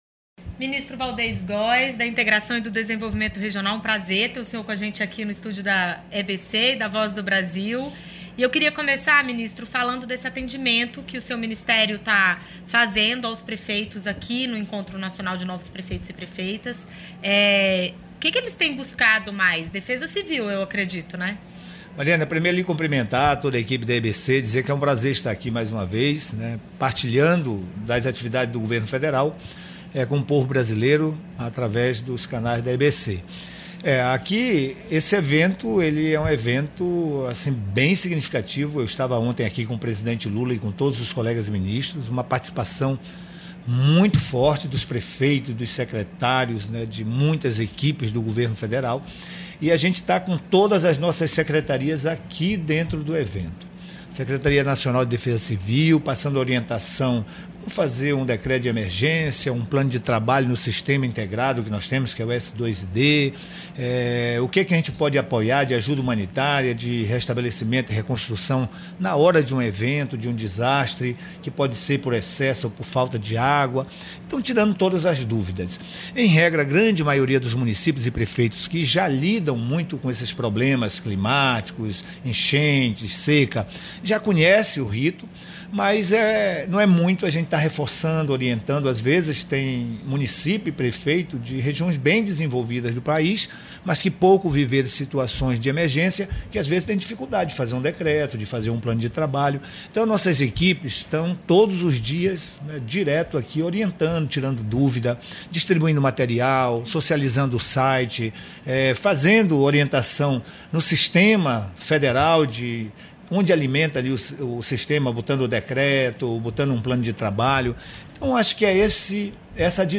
Em entrevista, a ministra falou sobre a liberação de novos recursos para a construção de unidades básicas de saúde por meio do PAC Seleções, anunciado por ela durante o Encontro de Novos Prefeitos e Prefeitas, que terminou nesta quinta-feira (13), em Brasília.